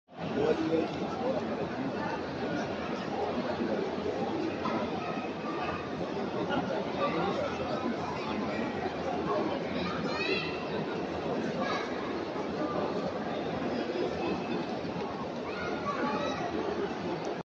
Islamabad Airport sound effects free download